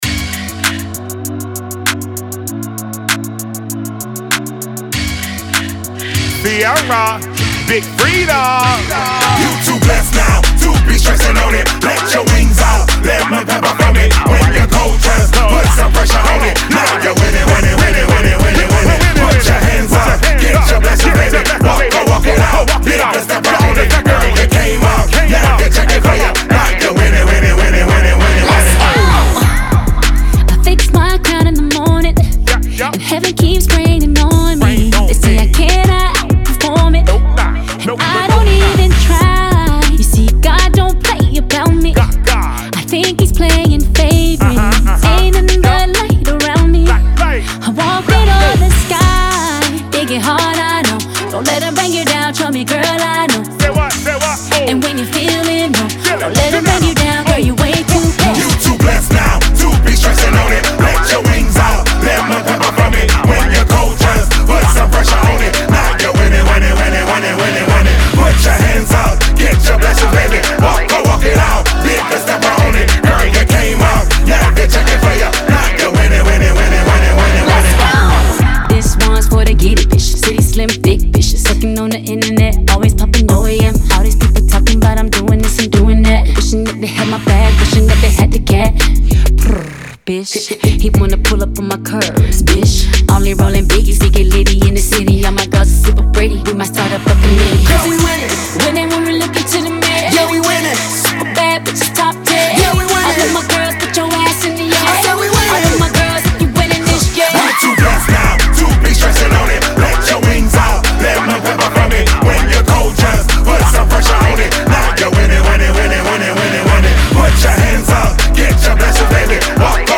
Genre : Soul